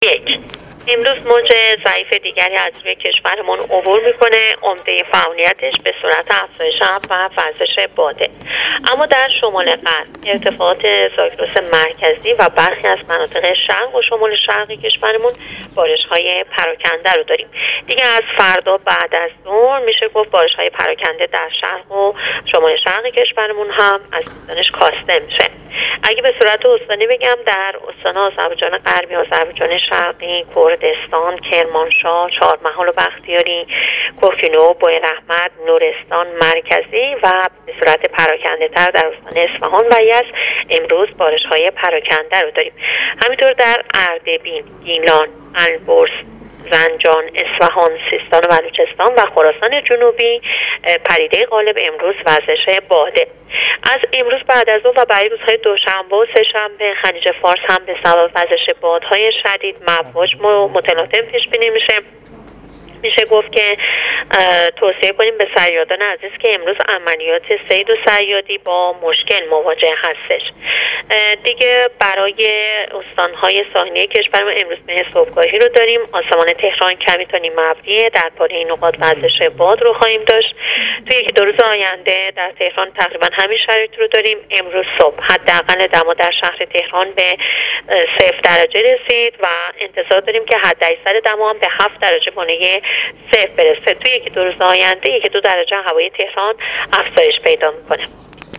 بشنوید: وضعیت آب و هوا در 24 ساعت آینده از زبان کارشناس هواشناسی/ دمای تهران به صفر رسید